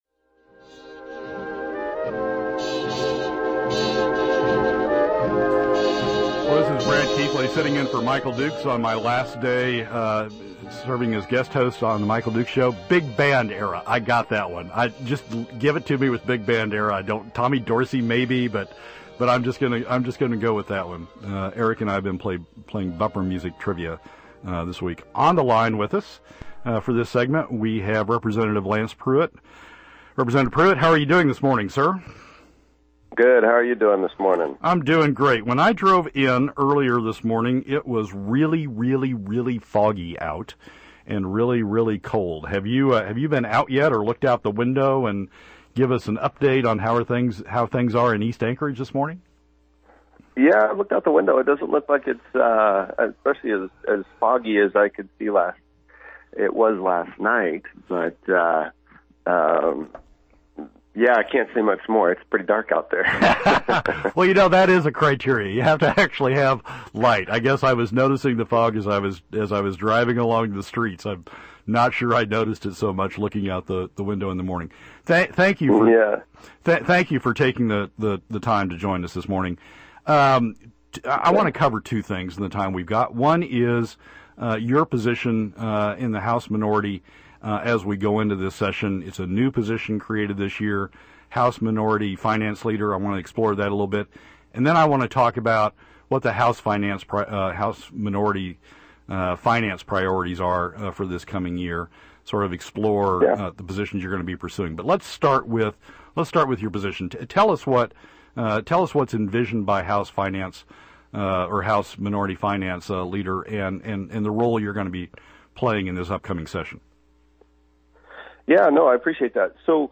During the conversation I had the opportunity to ask about that and other things.